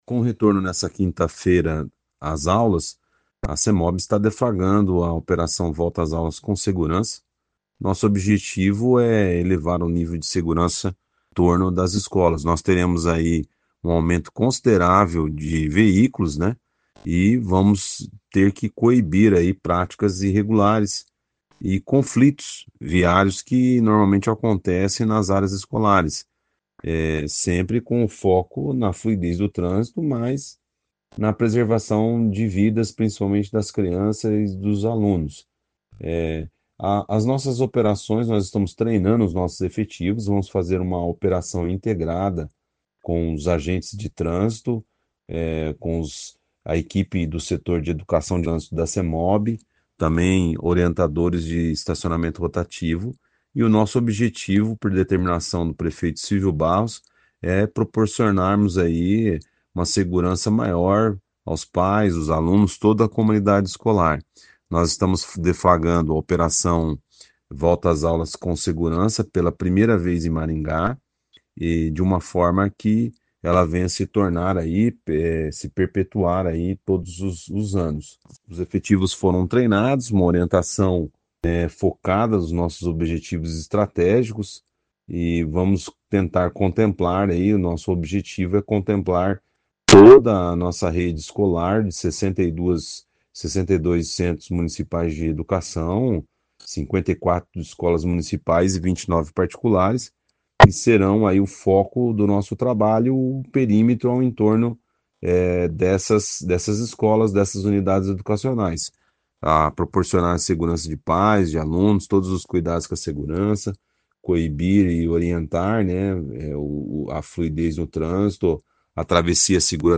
Ouça o que diz o secretário de Mobilidade Urbana, Luciano Brito.